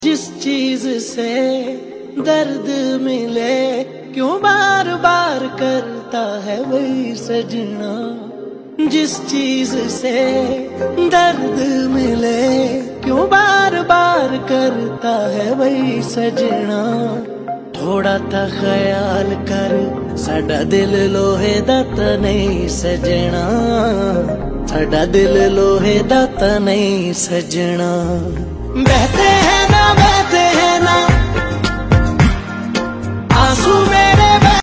heartbreak ballad
a beautiful melody that’s tinged with sadness.
guitars